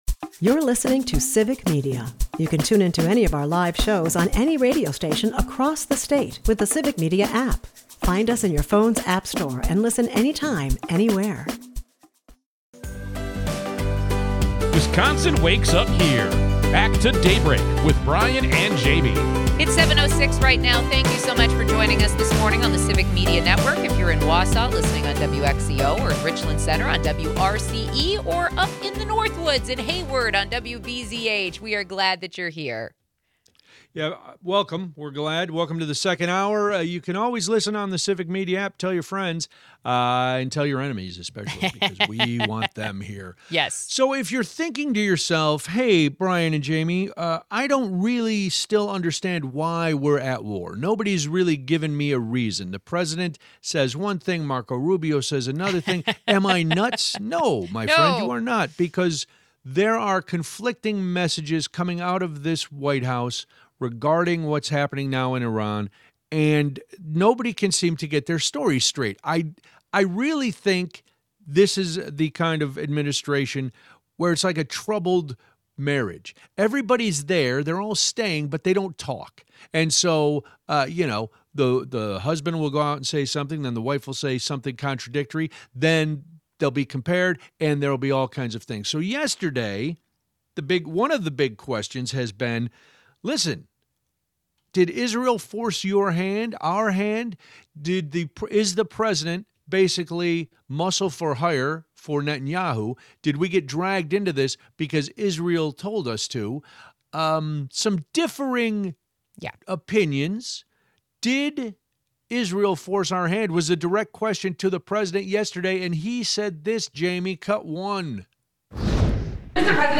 The Trump administration can’t get their lies straight. We play clips of various Trump officials, and the president himself, contradicting each other and even contradicting themselves. Criminal charges are being recommended by Wisconsin election officials in the case of Trump supporters trying to recall Wisconsin Assembly Speaker Robin Vos.